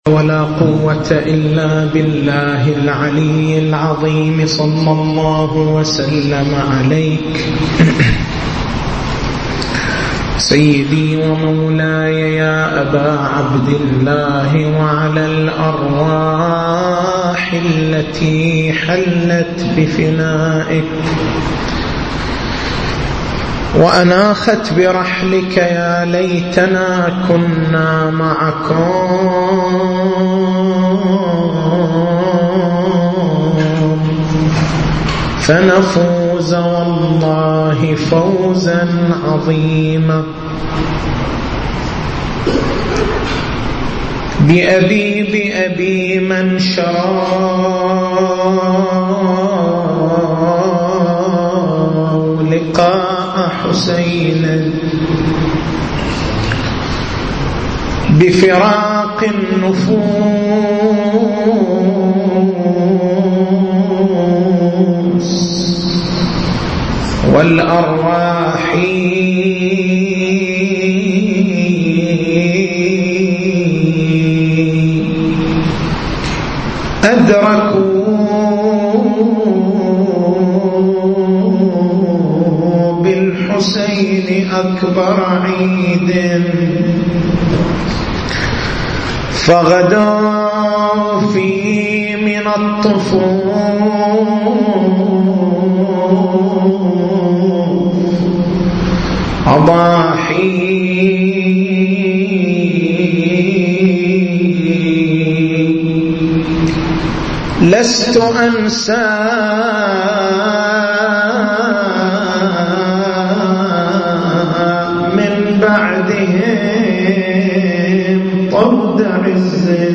تاريخ المحاضرة: 27/09/1433 نقاط البحث: ما هي النية؟ قصد العنوان قصد الوجه قصد القربة تنبيهان فقهيان هل ينبغي التلفظ بالنية؟ متى ينبغي للمصلي أن ينوي؟ ما هي أهمية النية في العبادات؟